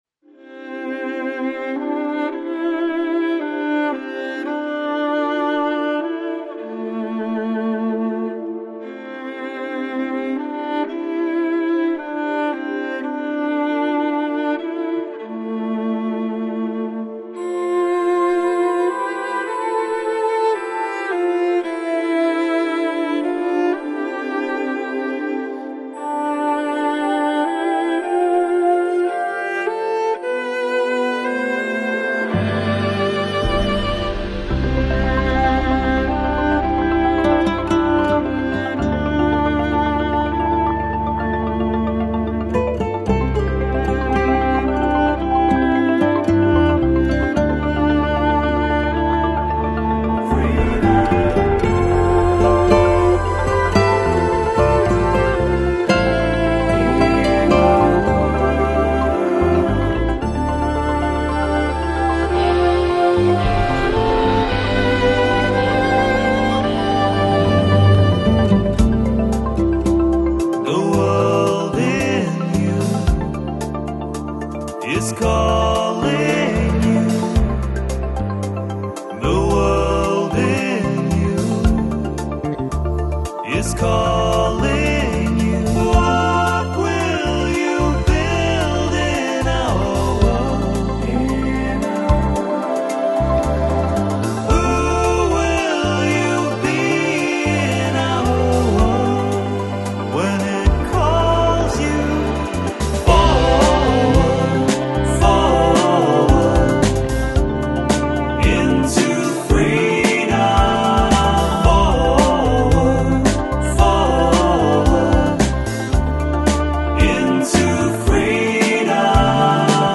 Жанр: New Age | Pop